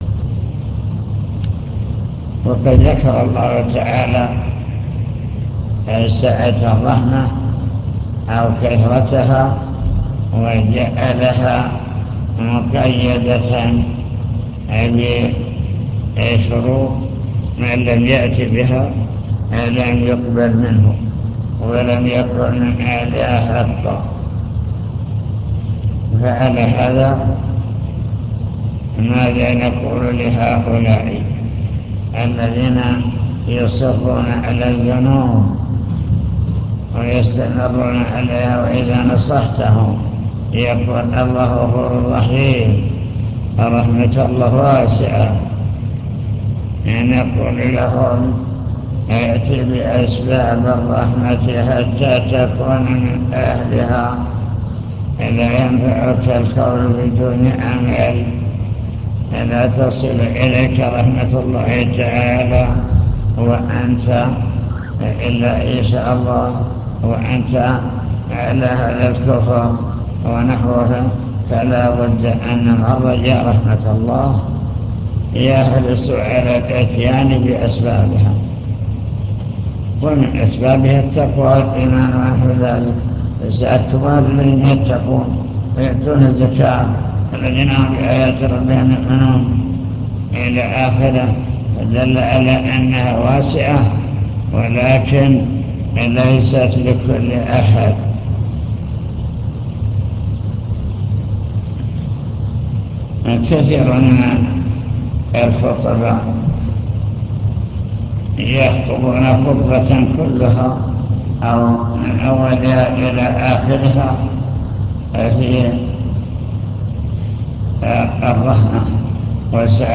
المكتبة الصوتية  تسجيلات - محاضرات ودروس  كتاب التوحيد للإمام محمد بن عبد الوهاب باب قول الله تعالى 'أفأمنوا مكر الله فلا يأمن مكر الله إلا القوم الخاسرون'